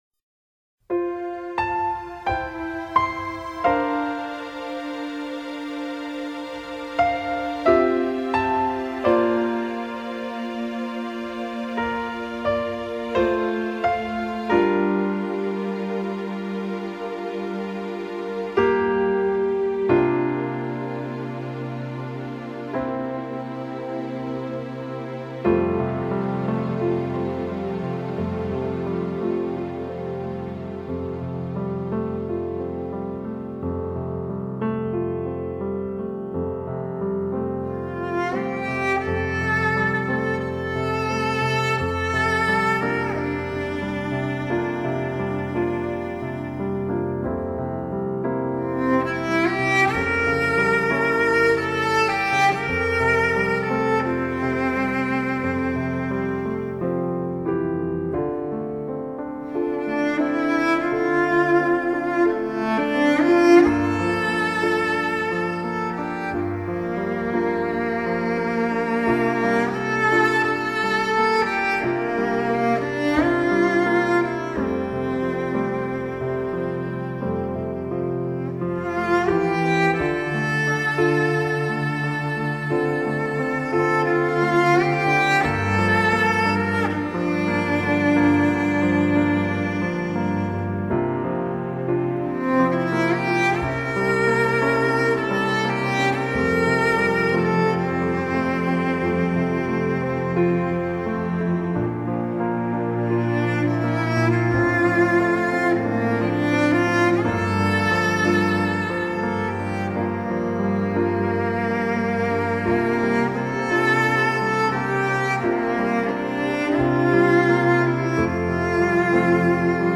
简单的音符在钢琴中化为点点滴滴的流水轻轻敲打在我的心头。
琴声在静谧中激起涟漪，轻柔的滑过指间，漫过心间，感觉如细雨般朦胧和清凉。
微妙的着色，包括小提琴，大提琴和单簧管混合着，与钢琴合奏着一个纯净而绿色的背景世界。